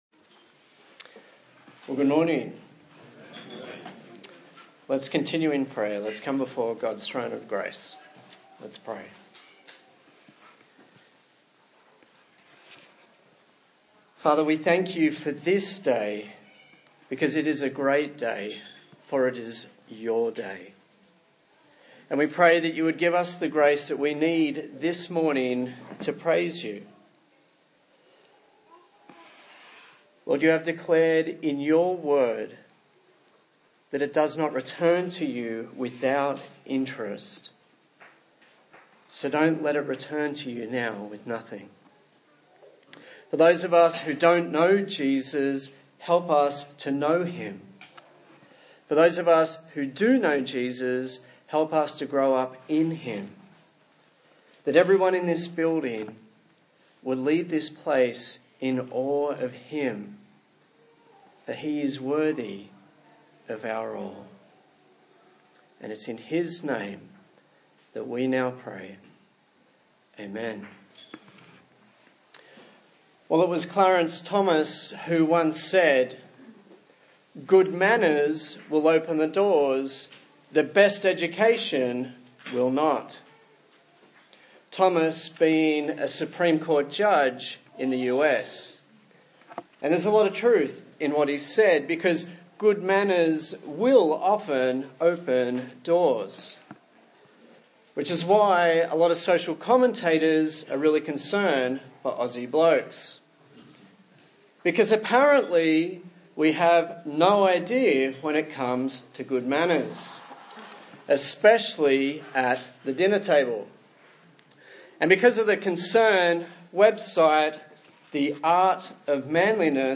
Luke Passage: Luke 14:1-14 Service Type: Sunday Morning